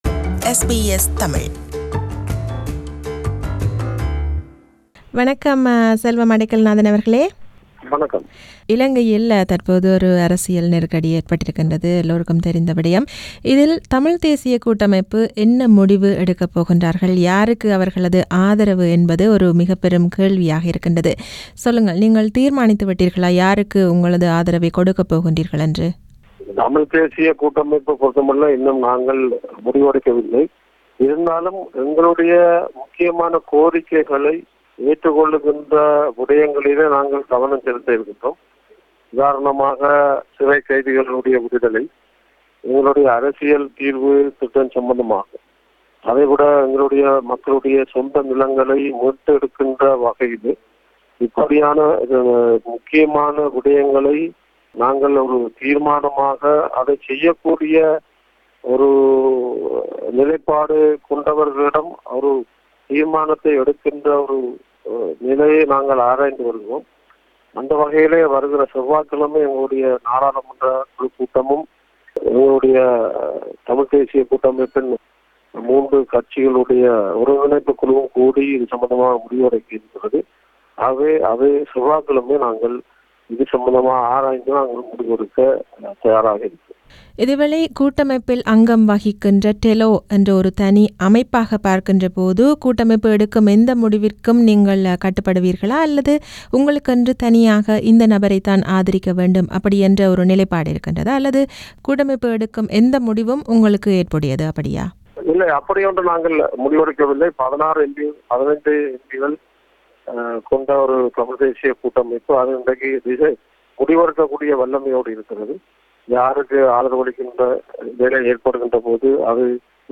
An Interview with Selvam MP
Sri Lanka's President Maithripala Sirisena has suspended parliament till November 16, a day after removing Ranil Wickremesinghe as prime minister and replacing him with former leader Mahinda Rajapaksa. This is an interview with Selvam Adaikalanathan-Leader of the Tamil Eelam Liberation Organization (TELO) and a member of the Tamil National Alliance (TNA) on SriLanka's political crisis.